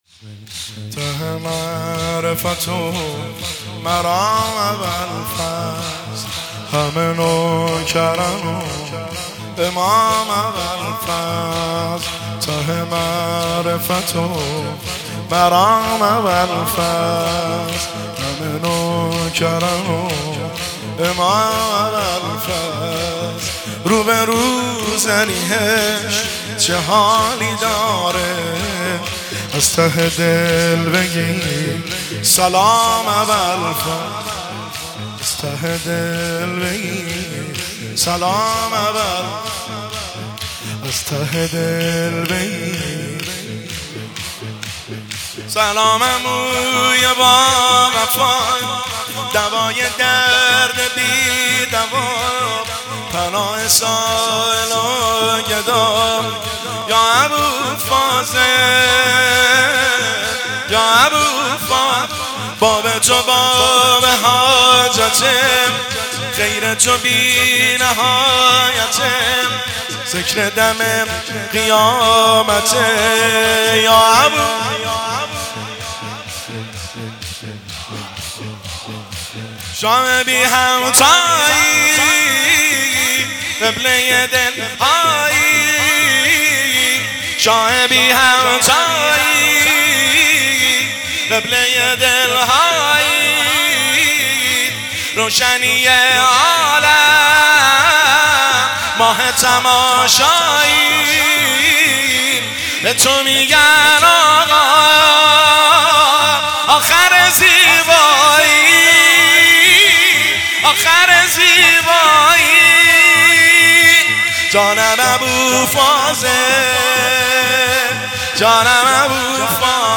مداحی شور
هیئت هفتگی 30 آبان 1403